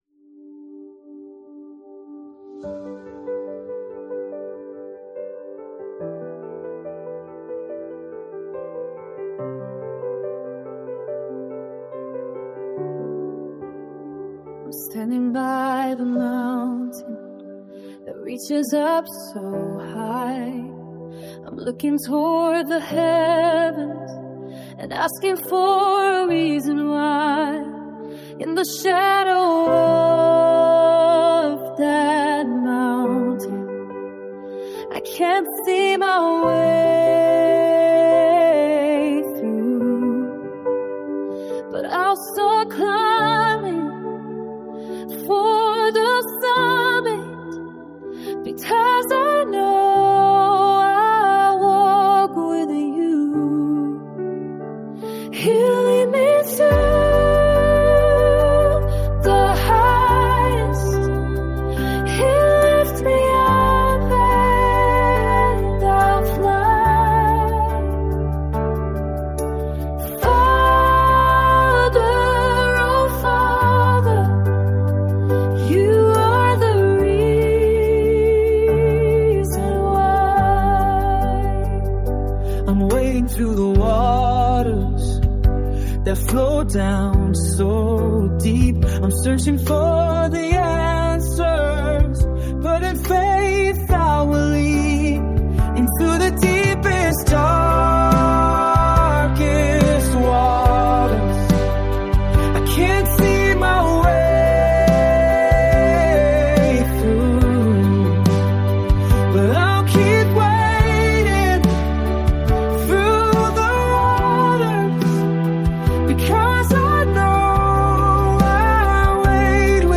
Beautiful song sung so very elegantly.
Such a reverent, tender duet.